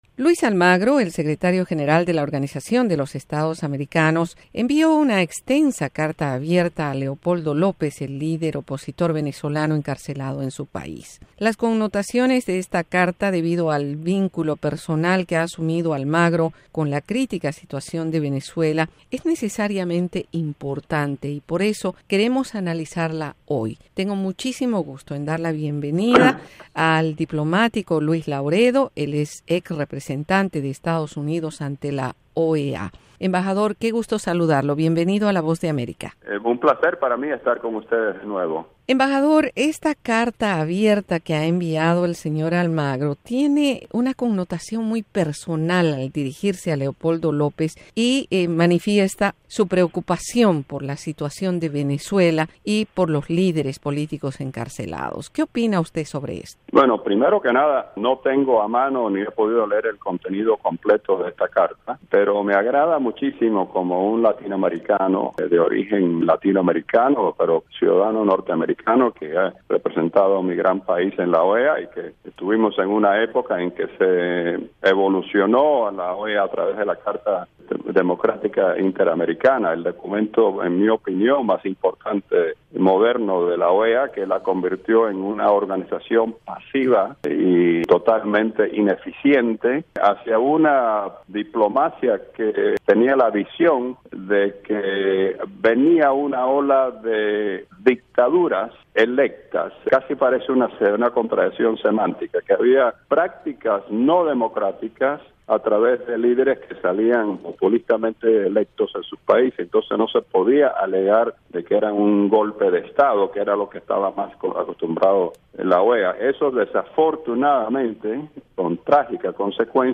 Luis Lauredo dialoga sobre la OEA y Venezuela